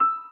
piano_last21.ogg